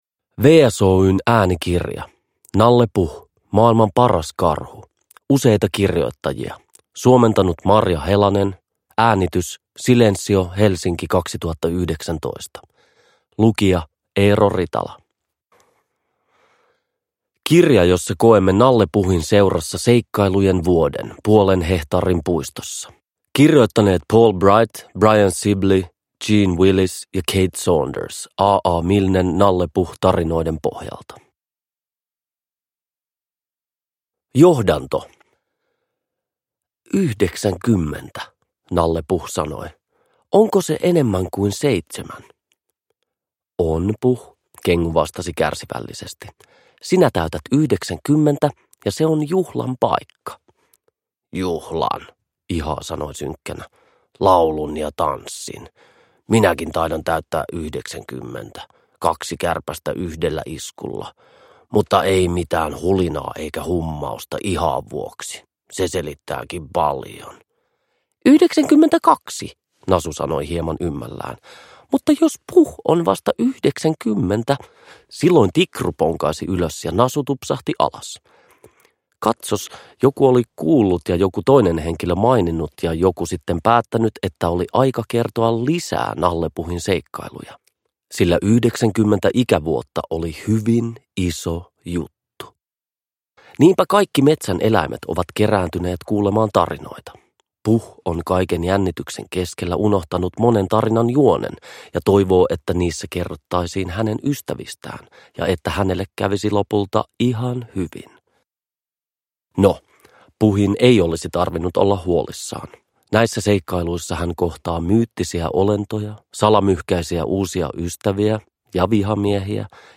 Nalle Puh. Maailman Paras Karhu (ljudbok) av Paul Bright | Bokon